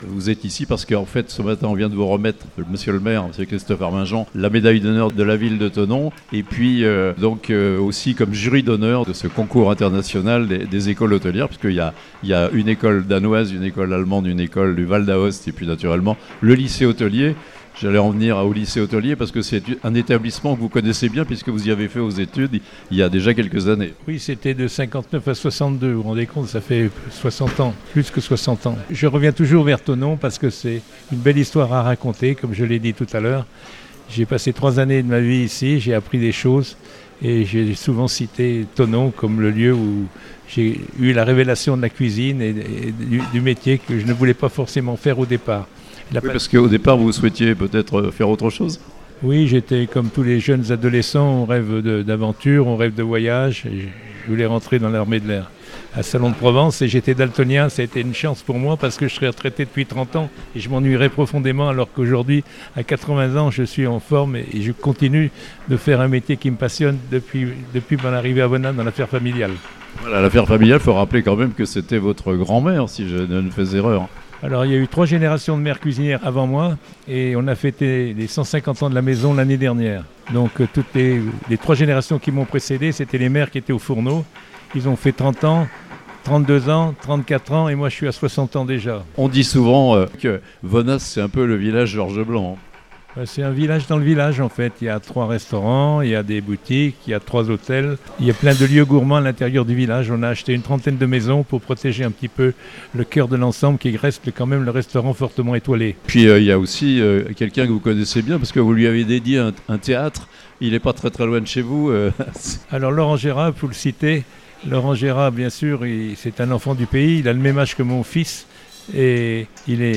La médaille d'honneur de la Ville de Thonon au Chef Georges Blanc (interview)